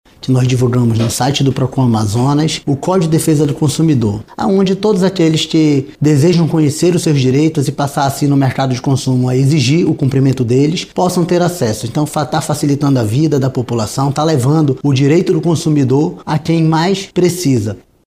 A medida implementada pelo Procon Amazonas busca promover a autonomia e o entendimento pleno das normas de proteção ao consumidor, como explica o diretor-presidente do Procon-AM, Jalil Fraxe.